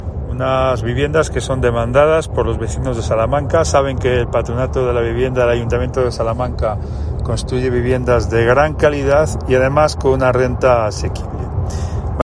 Carlos García Carbayo, alcalde de Salamanca